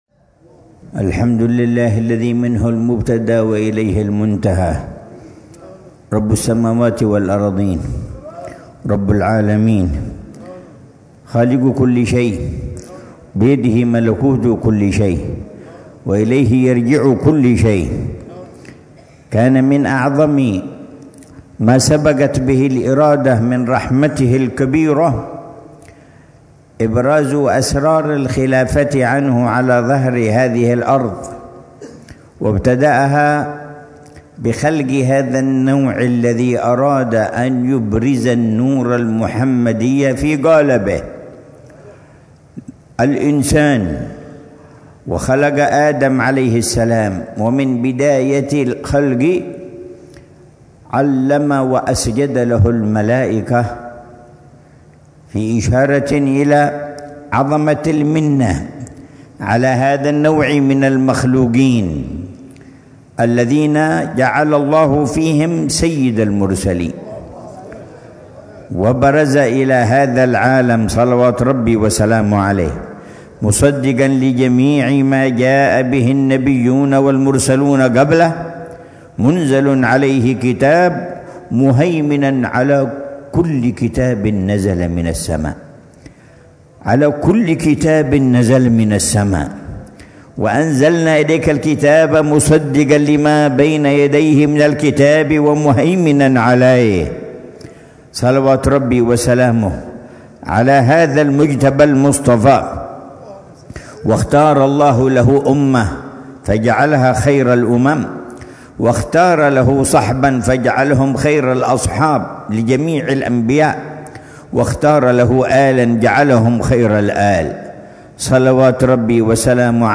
محاضرة العلامة الجبيب عمر بن محمد نن حفيظ ضمن سلسلة إرشادات السلوك، في دار المصطفى، ليلة الجمعة 13 شوال 1446هـ بعنوان: